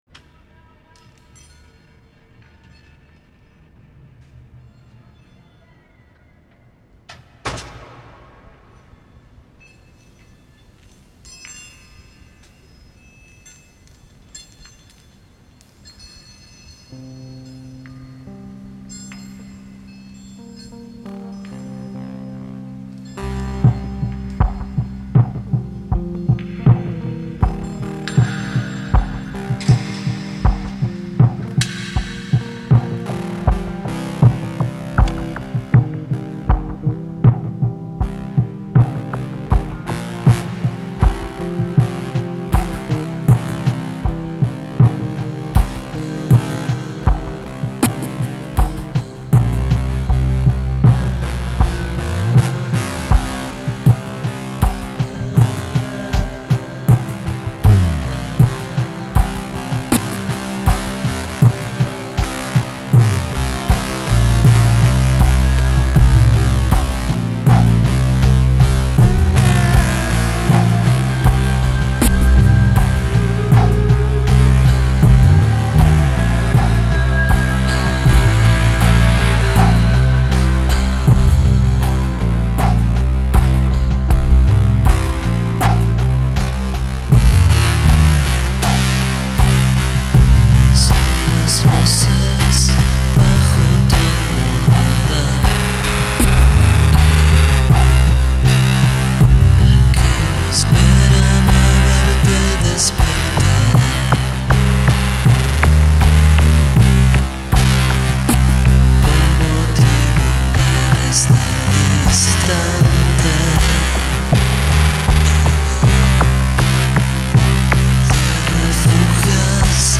COMPILATION OF ELECTRONIC MUSIC